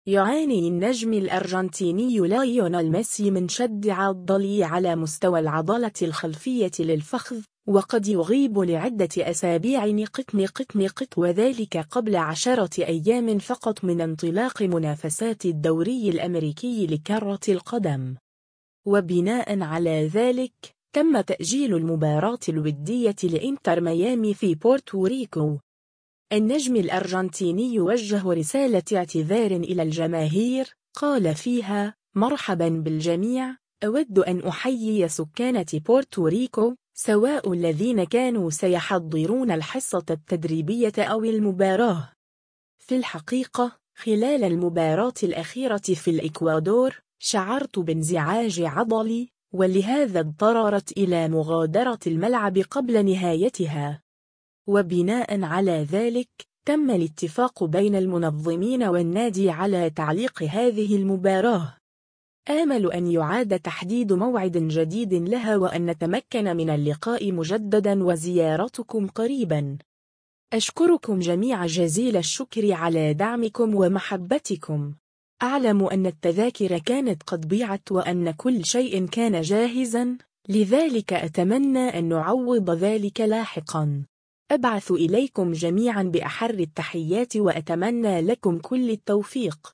النجم الأرجنتيني وجّه رسالة اعتذار إلى الجماهير، قال فيها : “مرحبًا بالجميع، أود أن أحيّي سكان بورتو ريكو، سواء الذين كانوا سيحضرون الحصة التدريبية أو المباراة.